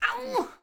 SFX_Battle_Vesna_Defense_03.wav